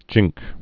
(jĭngk)